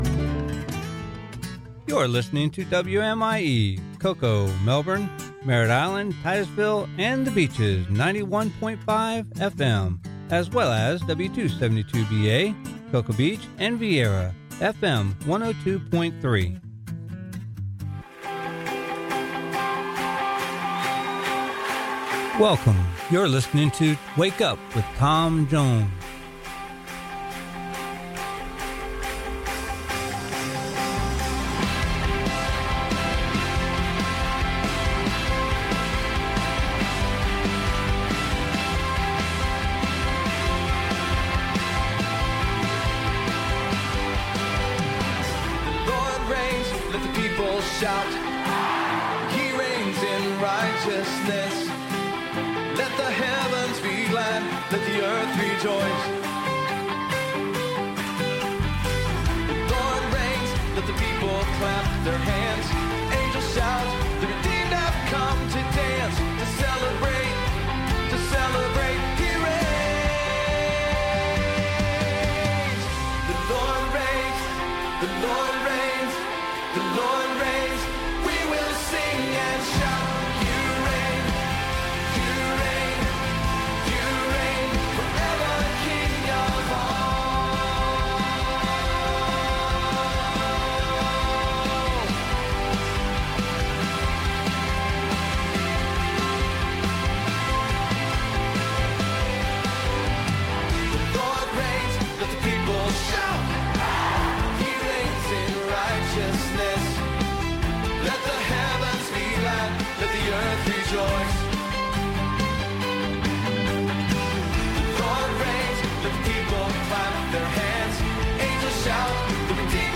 Sermon: Sermon on the Mount "Judge Not" Part 6 Ch. 7